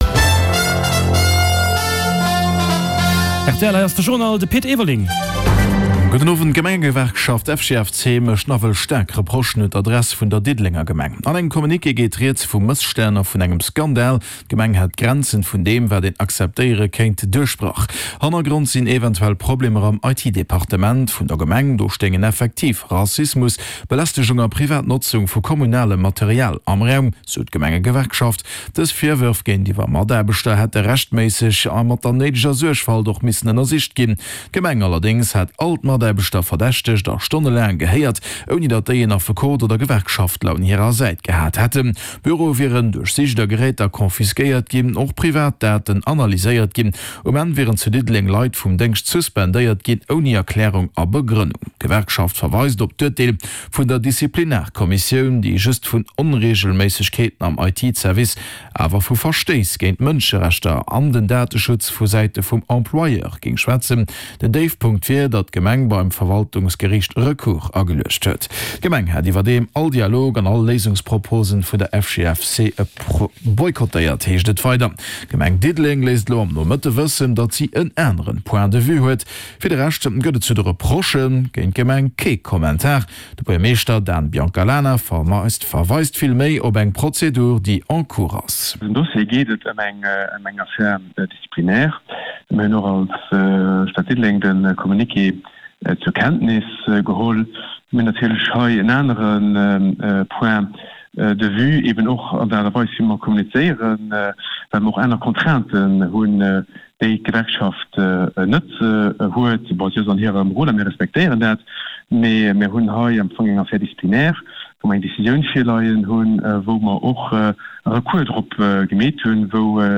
De grousse Journal vun RTL Radio Lëtzebuerg, mat Reportagen, Interviewën, Sport an dem Round-up vun der Aktualitéit, national an international